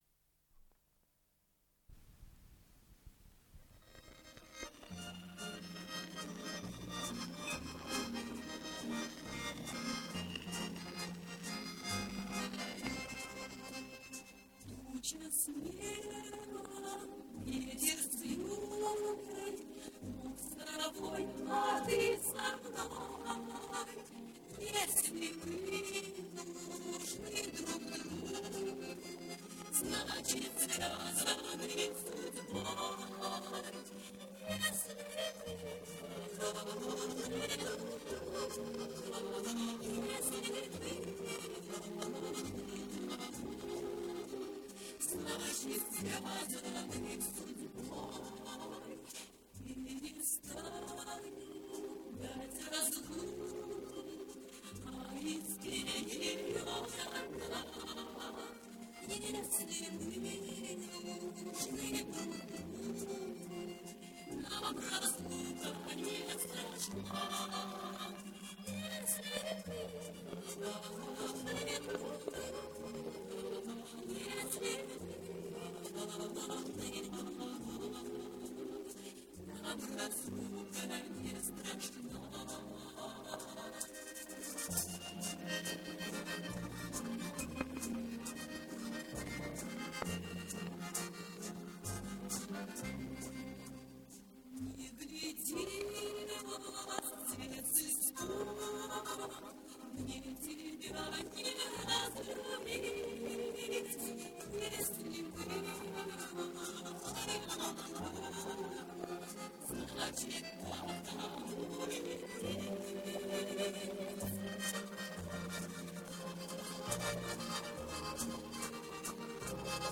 Ансамбль народных инструментов
Дубль моно.